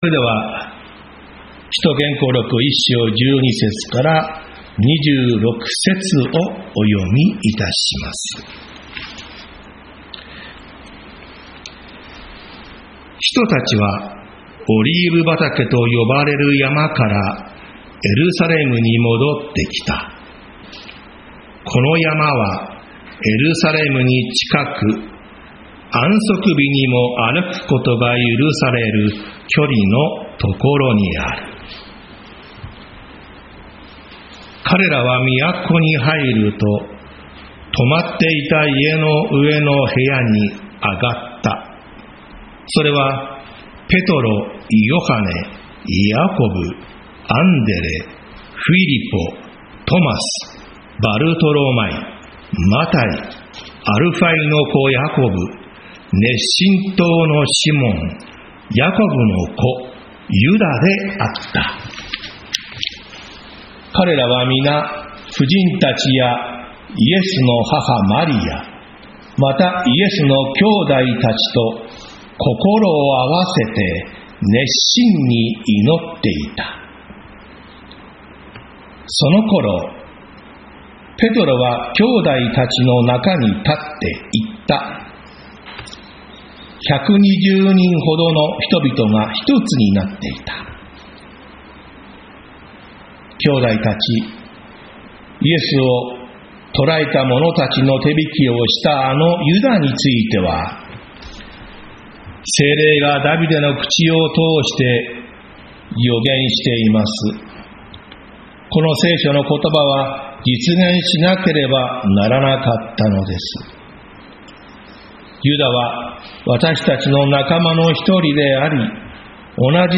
日曜 朝の礼拝